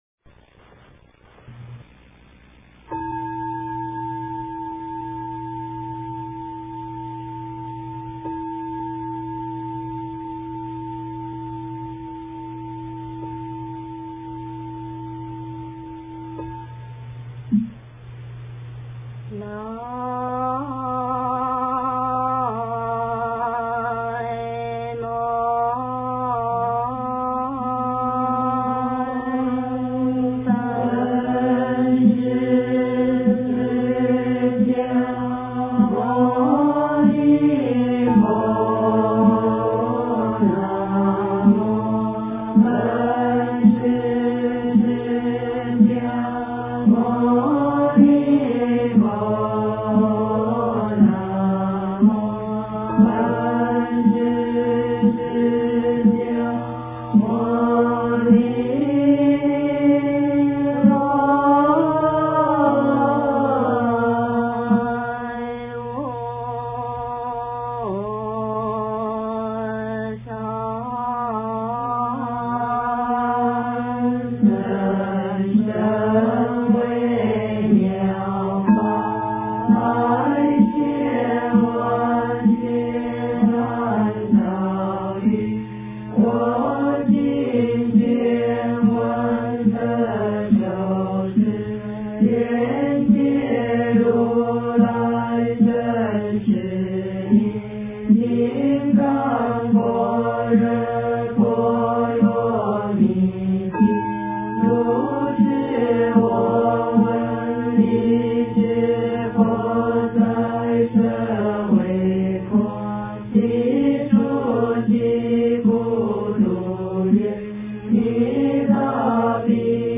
金刚经--僧团 经忏 金刚经--僧团 点我： 标签: 佛音 经忏 佛教音乐 返回列表 上一篇： 大悲咒.心经.七佛灭罪真言.补阙真言.赞--圆光佛学院众法师 下一篇： 金刚经--法师 相关文章 晚课--圆光佛学院女众 晚课--圆光佛学院女众...